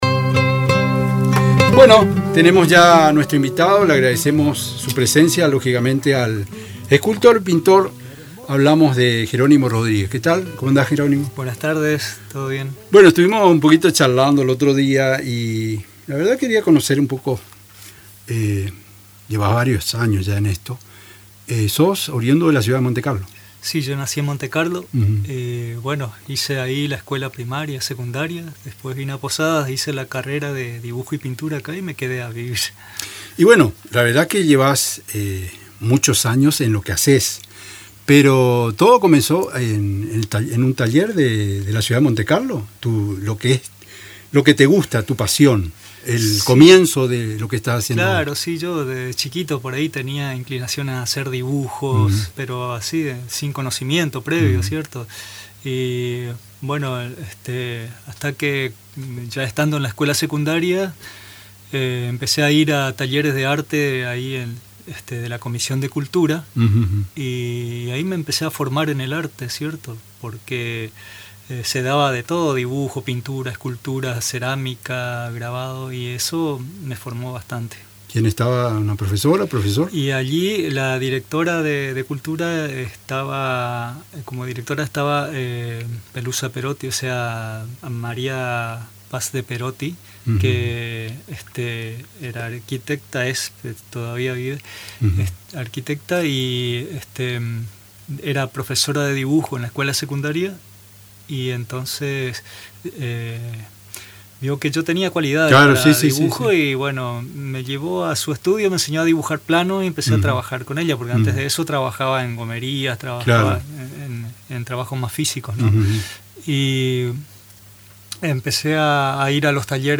En una entrevista con Radio Tupa Mbae